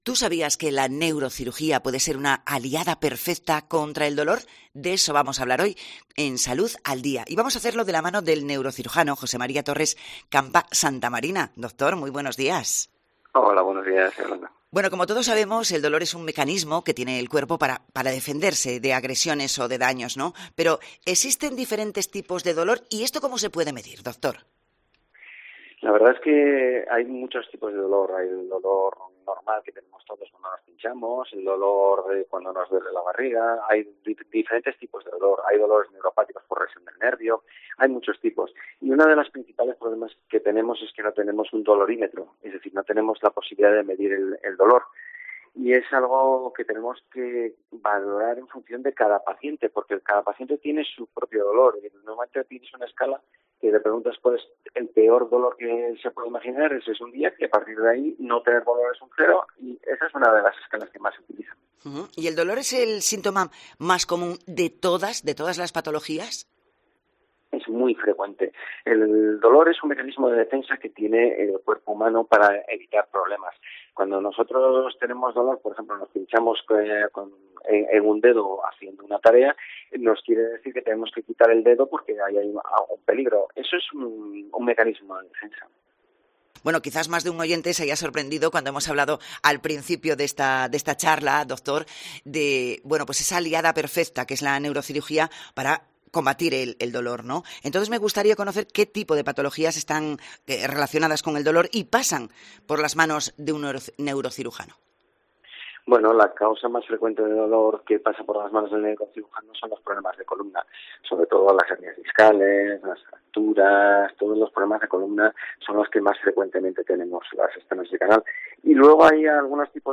Salud al Día: entrevista